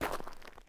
mining sounds